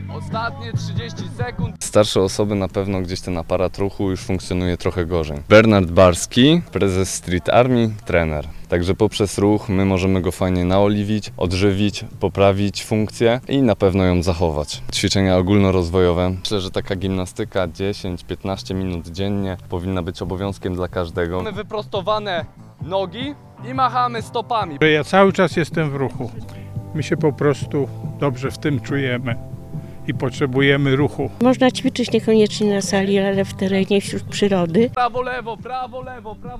Skłony, przysiady i wiele innych ćwiczeń wykonywali wczoraj seniorzy z Uniwersytetu Trzeciego Wieku w Parku Piastowskim.